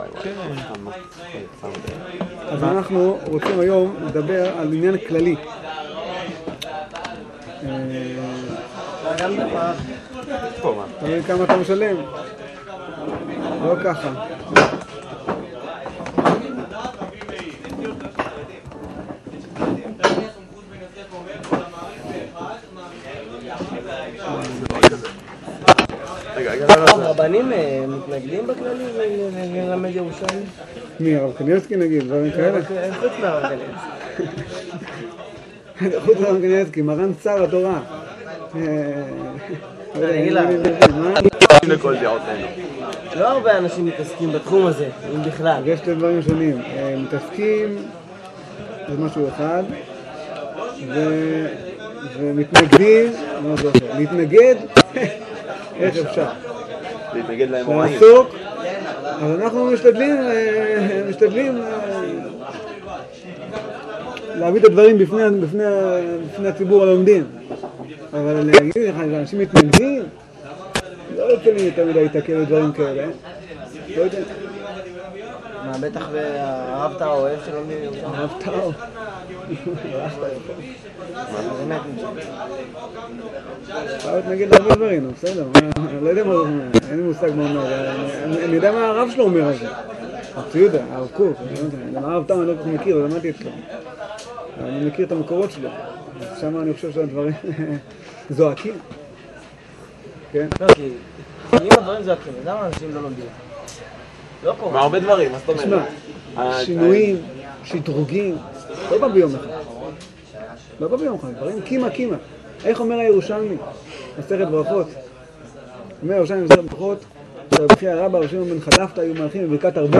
שיעור הלכה ואגדה